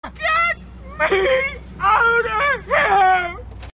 get me outta here Meme Sound Effect
Category: Reactions Soundboard